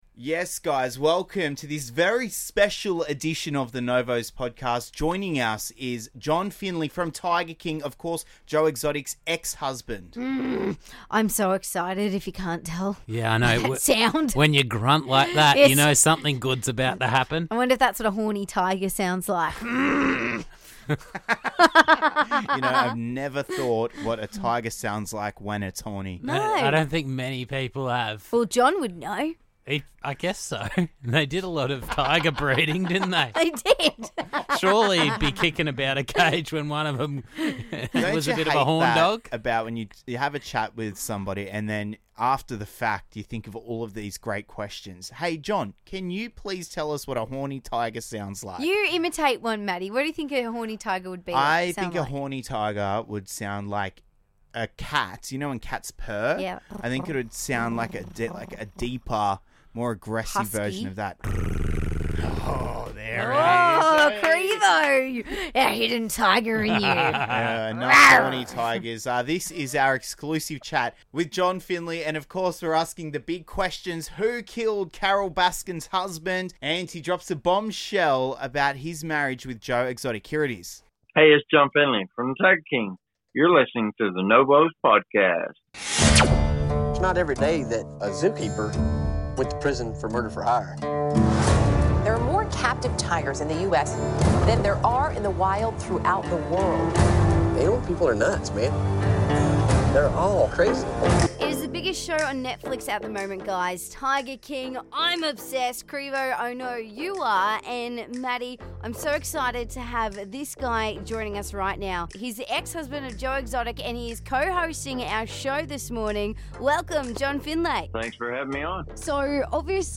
EXCLUSIVE Interview with John Finlay from Tiger King!